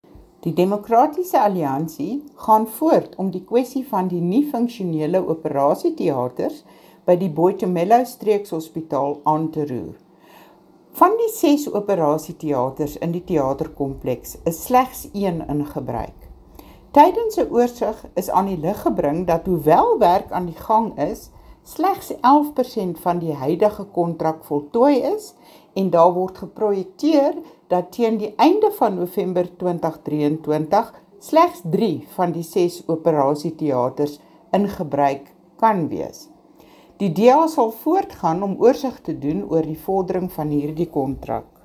Afrikaans soundbites by Mariette Pittaway MPL and